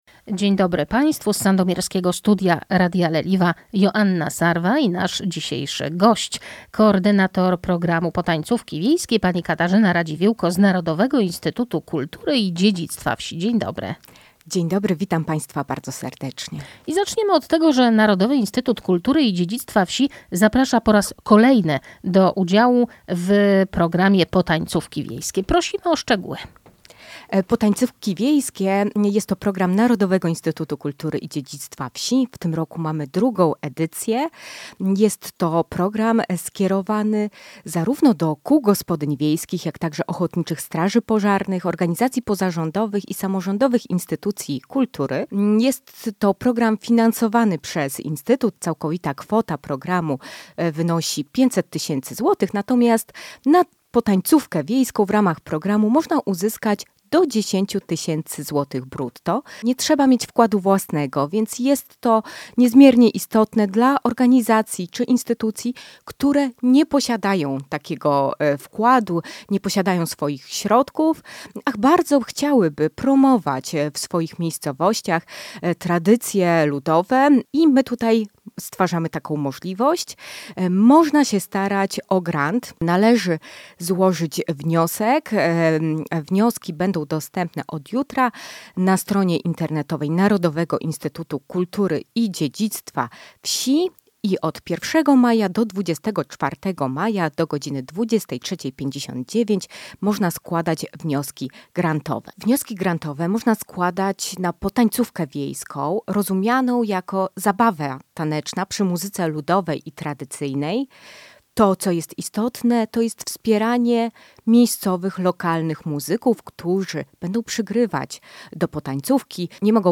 Narodowy Instytut Kultury i Dziedzictwa Wsi zaprasza do udziału w kolejnej edycji Programu "Potańcówki Wiejskie". O szczegółach mówi Gość Radia Leliwa -